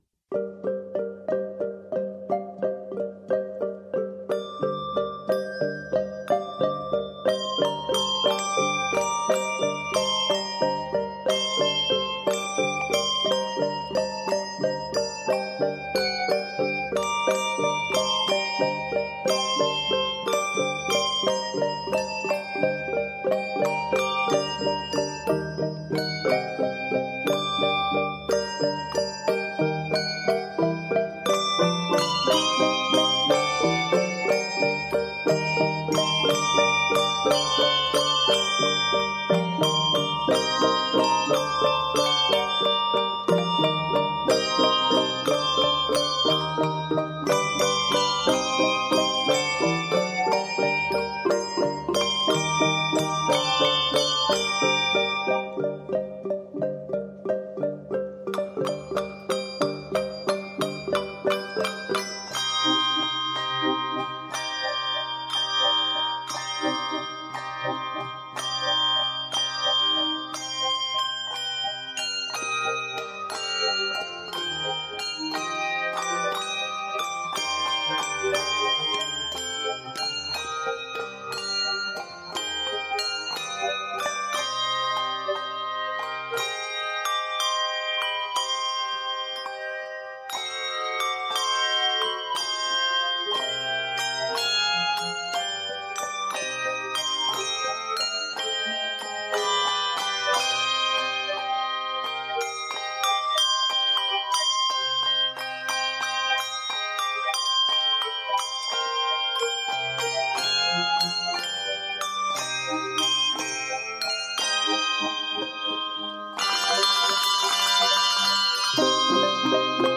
upbeat setting of a traditional French carol